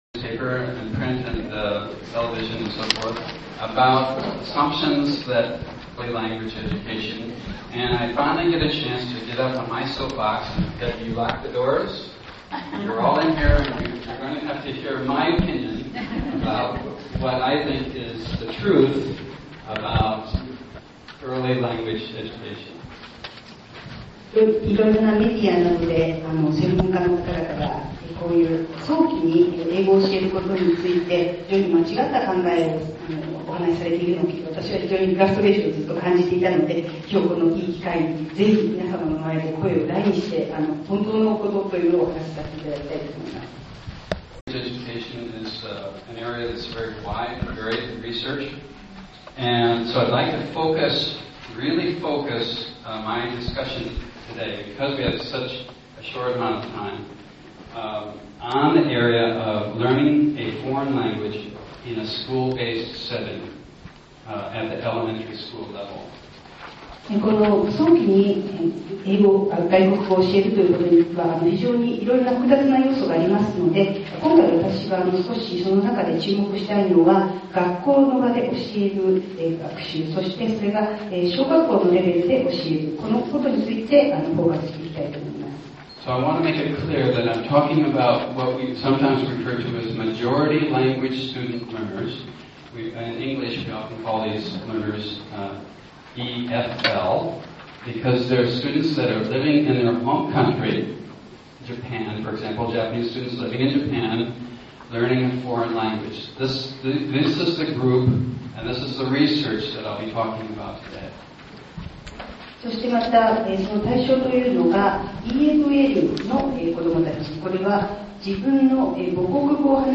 Photos & Sound Clips from the JALT 2005 Pan-SIG Conference
Plenary Clip 1